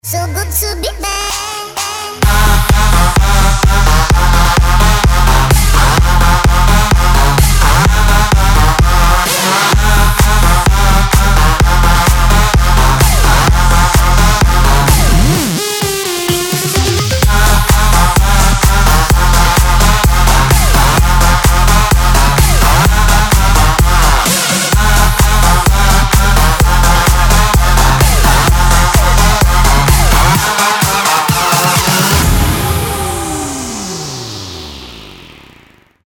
• Качество: 192, Stereo
Новый Датч-Хаус трек